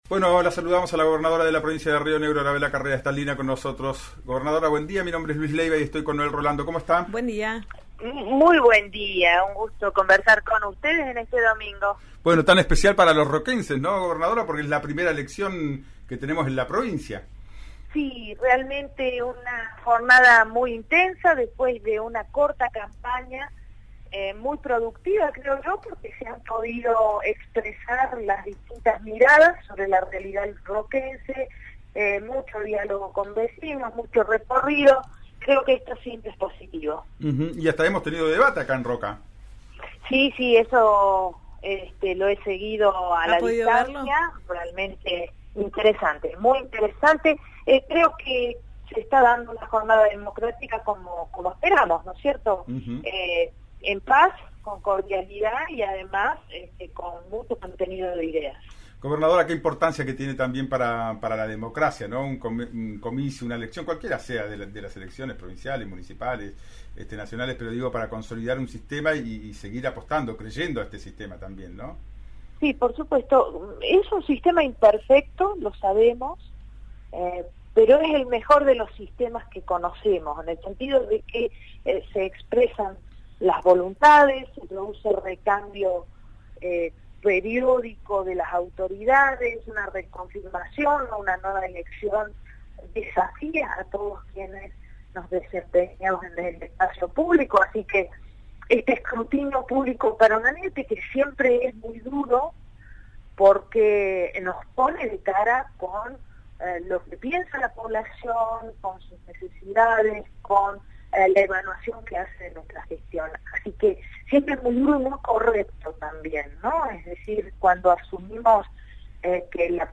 La gobernadora de Río Negro habló con Río NEGRO RADIO.